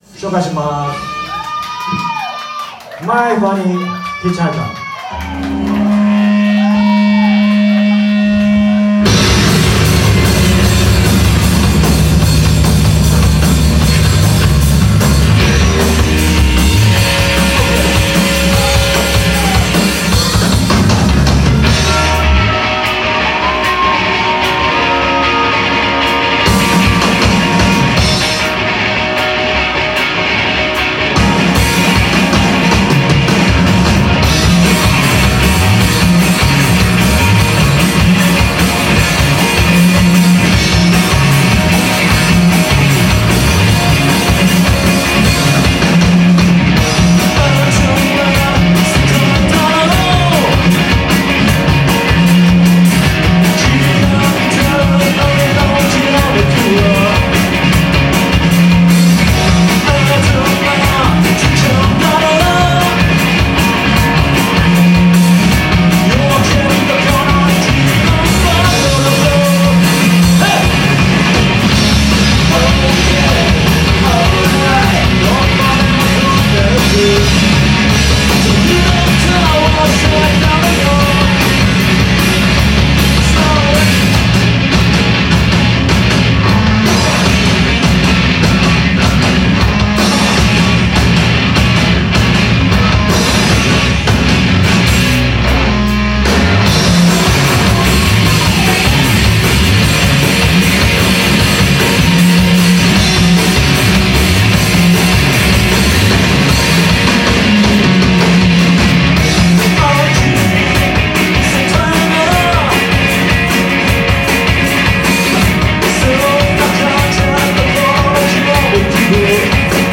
東京下北沢デイジーバーにて昼ワンマン終了。